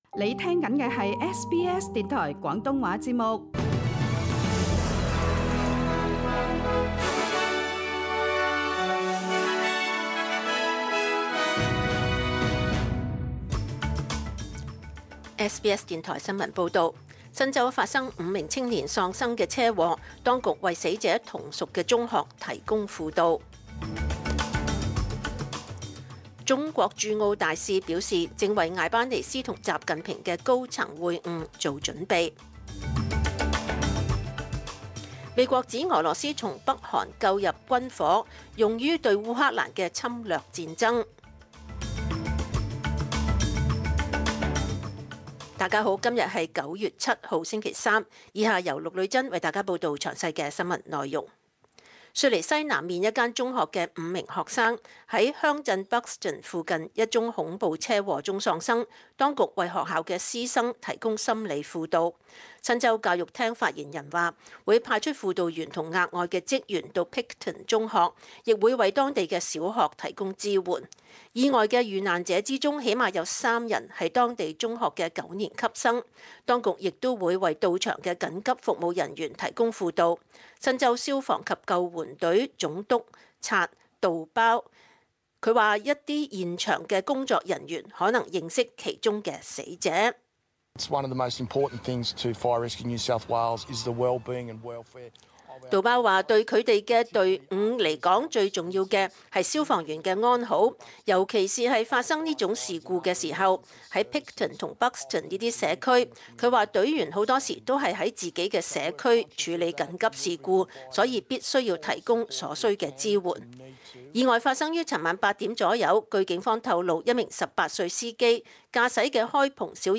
SBS 廣東話節目中文新聞 Source: SBS / SBS Cantonese